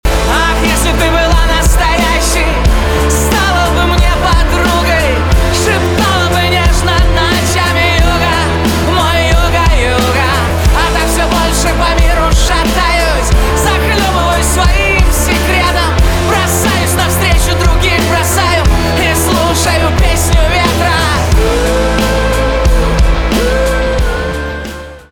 поп
грустные
барабаны , гитара , рок , печальные